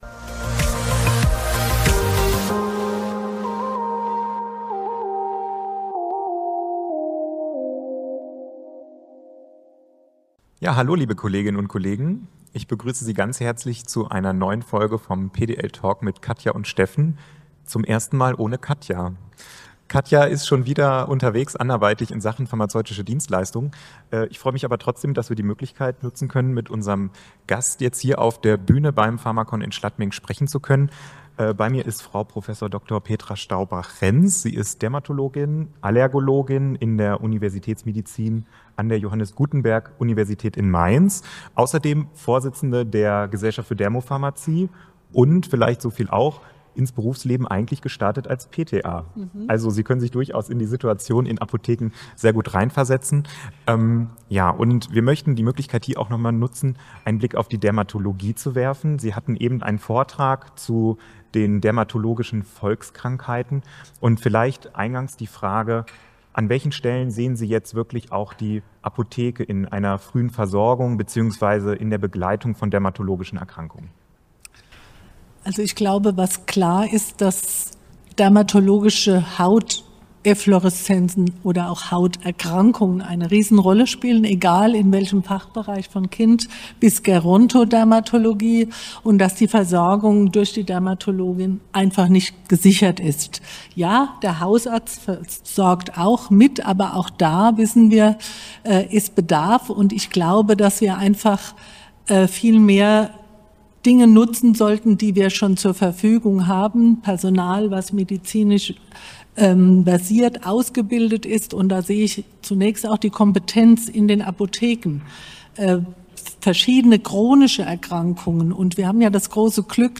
Live vom pharmacon